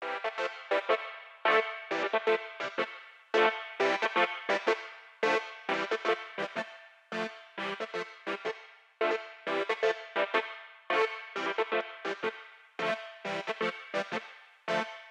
synthe medium.wav